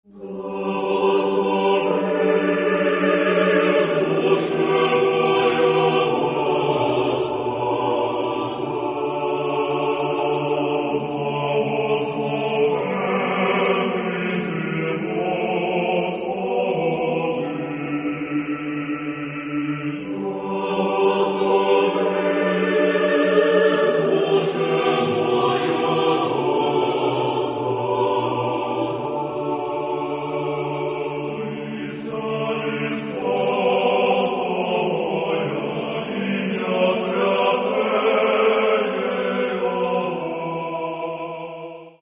Каталог -> Классическая -> Хоровое искусство